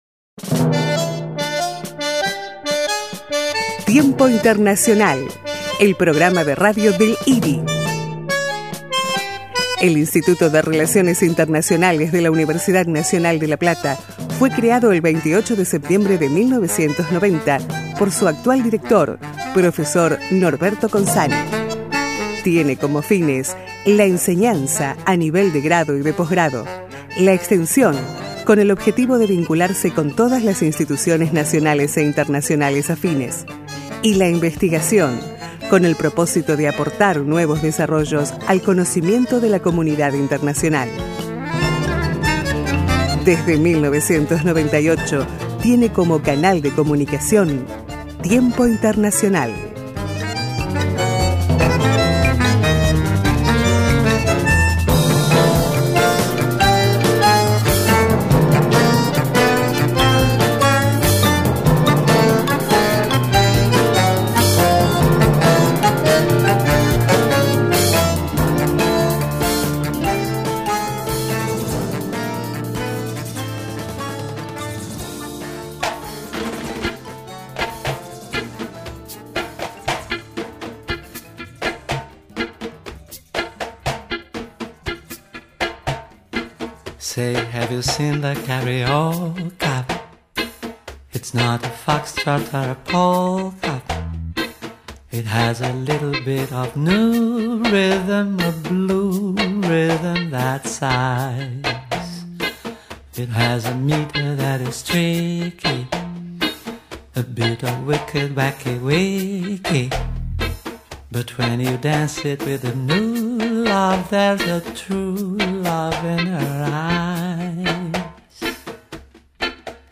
Entrevista al Profesor de la Universidad de Pernambuco